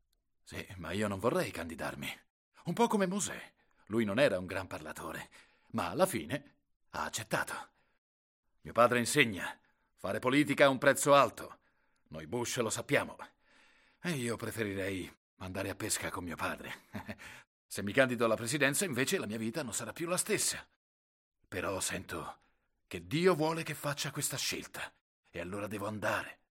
W.", in cui doppia Josh Brolin.